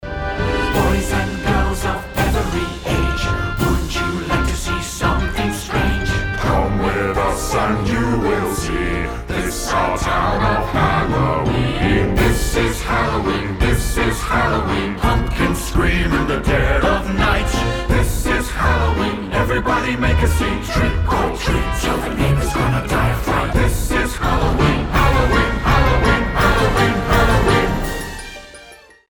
• Качество: 320, Stereo
пугающие
праздничные
хор